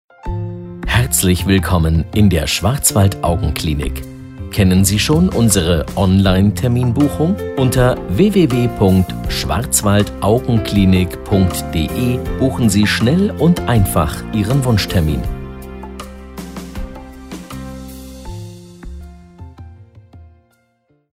Telefonansage Klinik – Krankenhaus – Spital